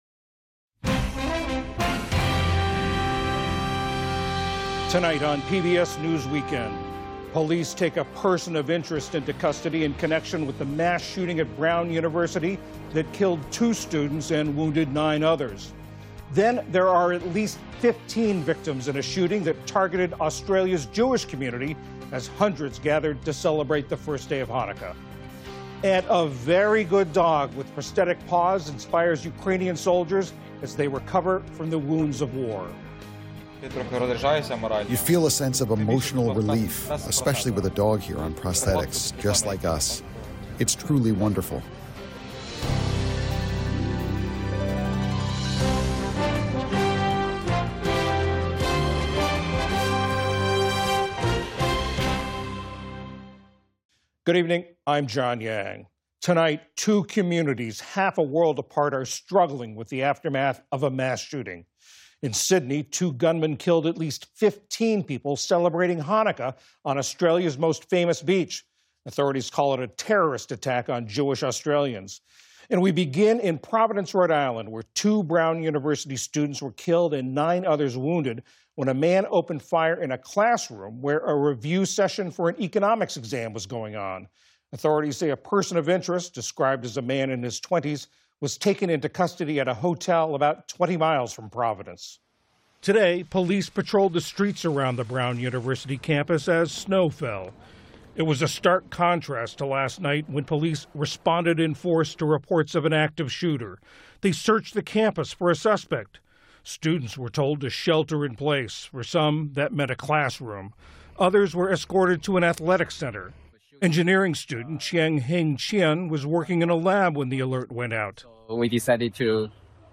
PBS NewsHour News, Daily News